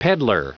Prononciation du mot peddler en anglais (fichier audio)
Prononciation du mot : peddler